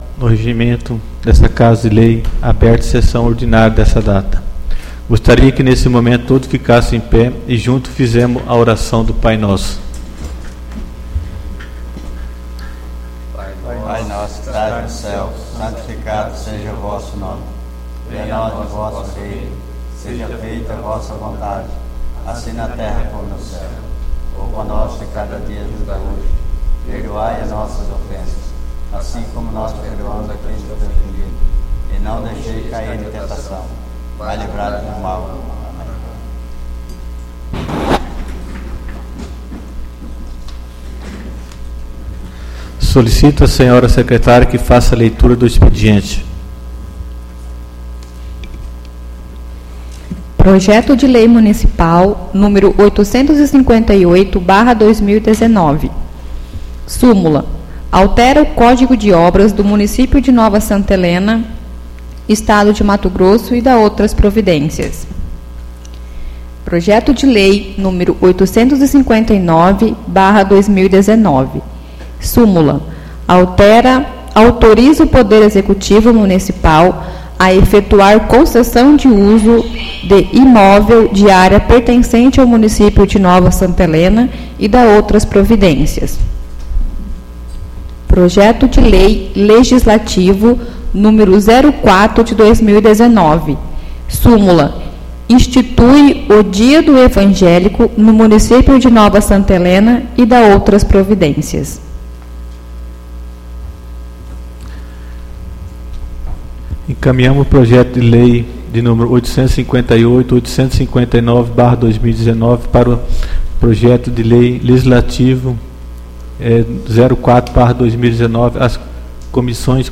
Sessão Ordinária do dia 03/09/2019